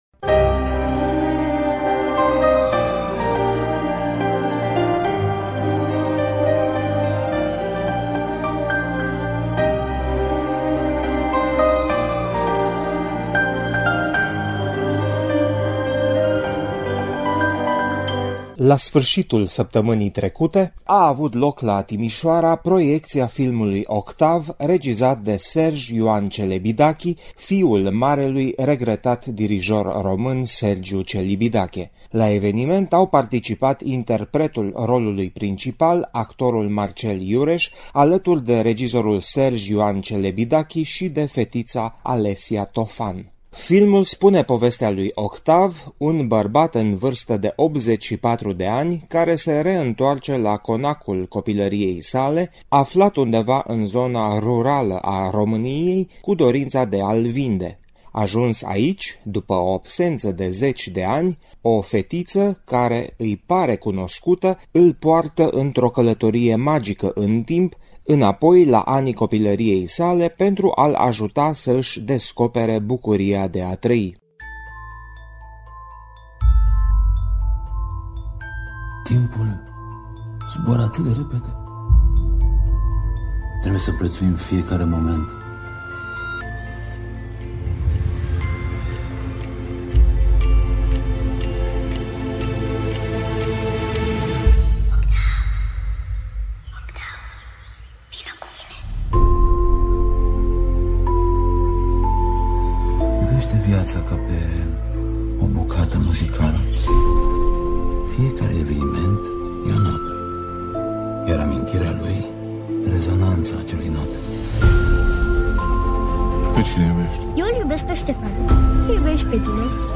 Audiaţi interviurile acordate de Marcel Iureş şi Serge Ioan Celebidachi